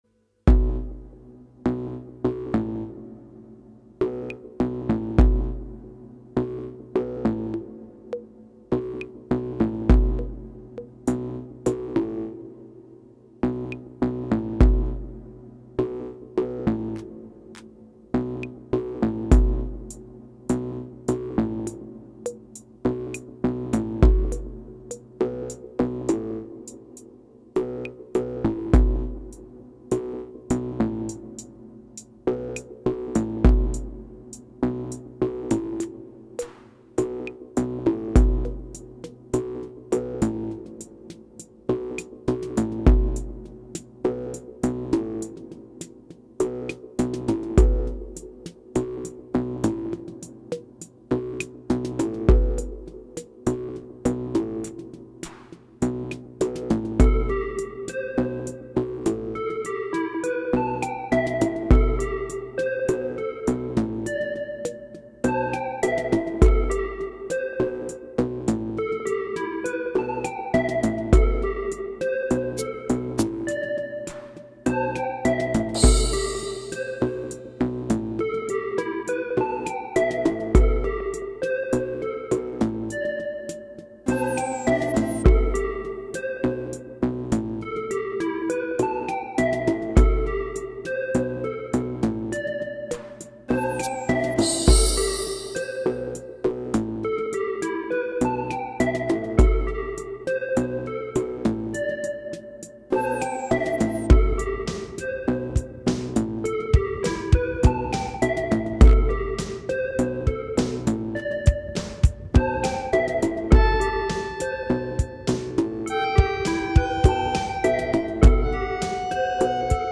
Live analog synth music from my standing rack, 2023-2034
Slower and moodier than I usually go.